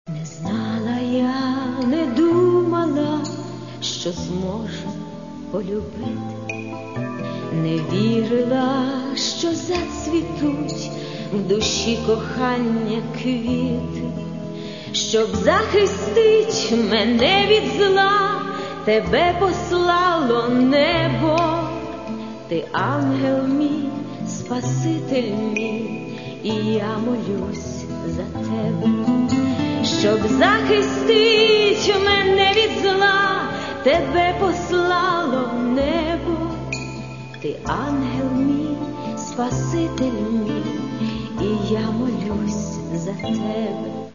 Каталог -> Естрада -> Співачки